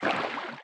Sfx_creature_brinewing_swim_fast_04.ogg